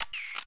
ui_camera_shutter_6.wav